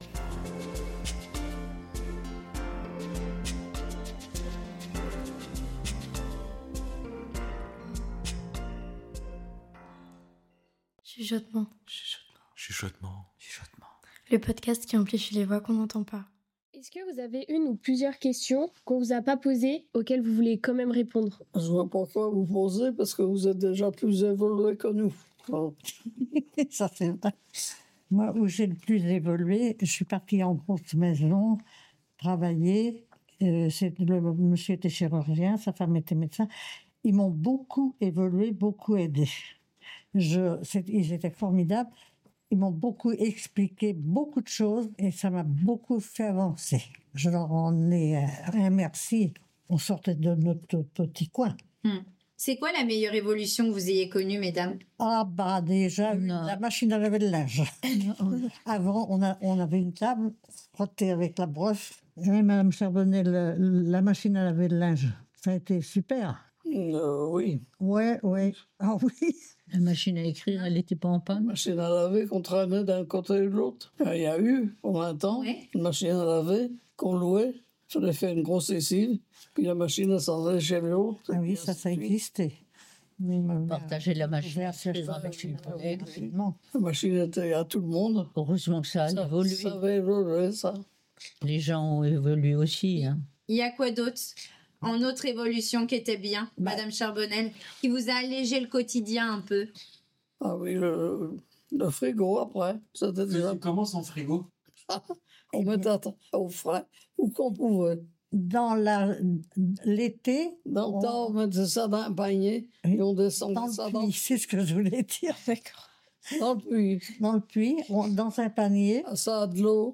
Cet épisode est le troisième et dernier d'une série réalisée avec les résident·es de l'EHPAD Vivre ensemble à La Jumellière. En 3 étapes, nous plongeons dans leurs récits de jeunesse, leurs histoires émouvantes et les moments marquants de leur vie. Dans cet épisode, on parle des évolutions technologiques qui ont marqué leur vie, des alternatives aux produits du quotidien en temps de guerre et de dialogue entre les époques.